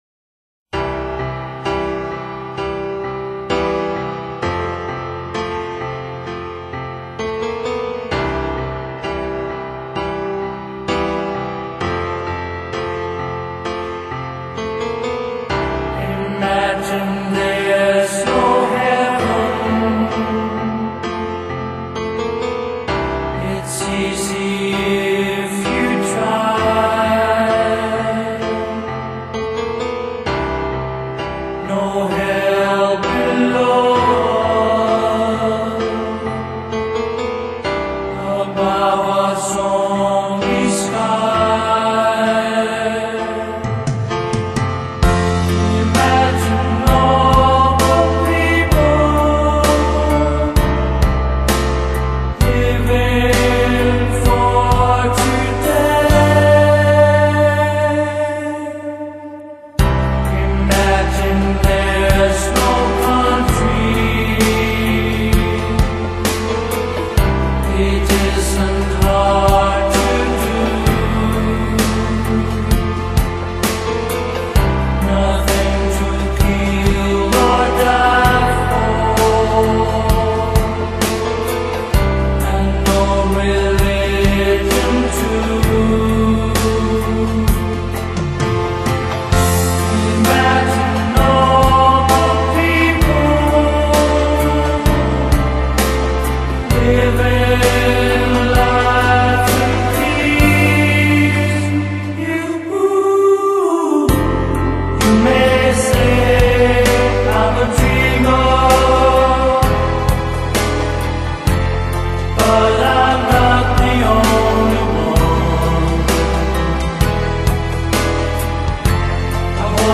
덴마크의 올레보르그와 버글럼(Aalborg와 Bøglum) 수도원의 수사님들 노래